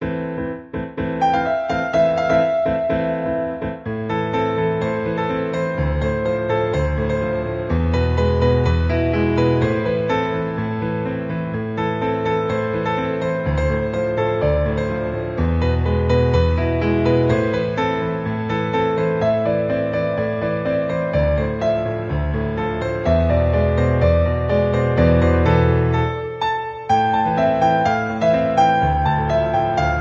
• Key: A Minor
• Instruments: Piano solo
• Genre: Pop, Film/TV